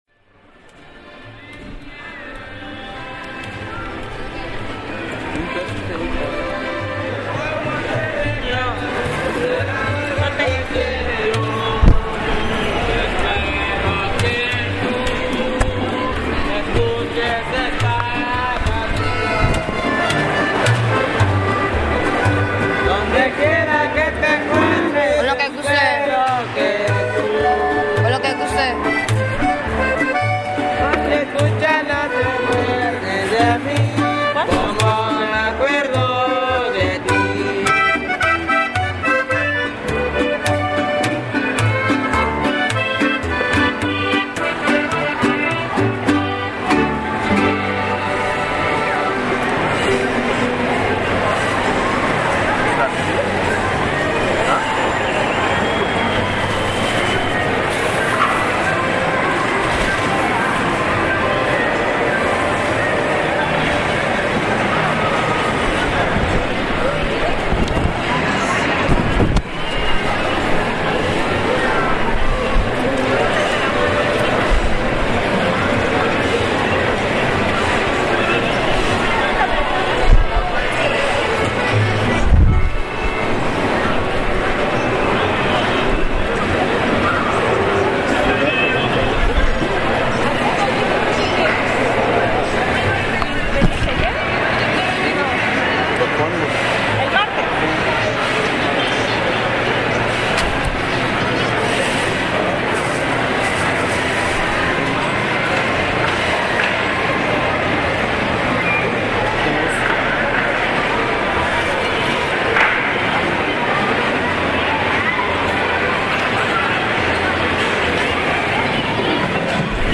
Norteños
Hoy temprano un grupo de hombres uniformados de vaquero, botas y sombrero, recorrieron los pasillos, interrumpiendo el sonido cotidiano del lugar, vendedores y compradores los veían con curiosidad y hasta uno que otro se movió al ritmo de la música mientras esperaba ser atendido por el vendedor de frutas; el más joven de los músicos con sombrero en mano se acercaba a pedir unas monedas.